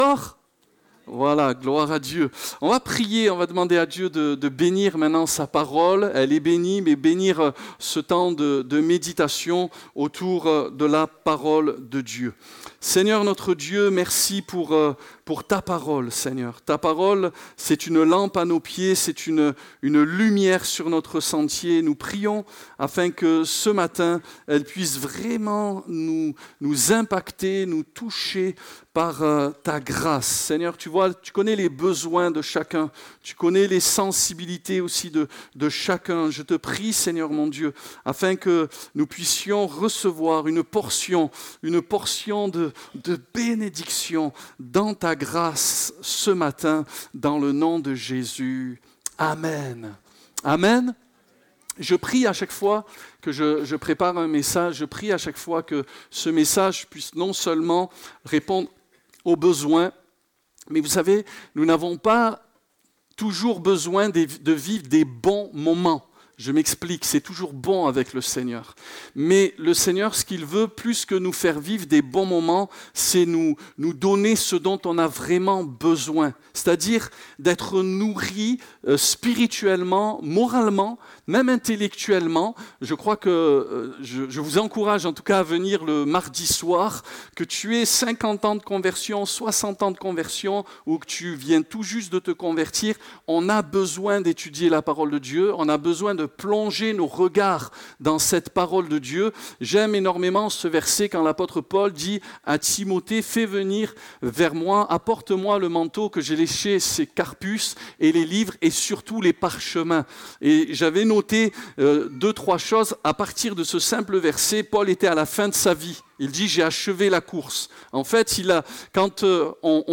Découvrez en replay vidéo le message apporté à l'Eglise Ciel Ouvert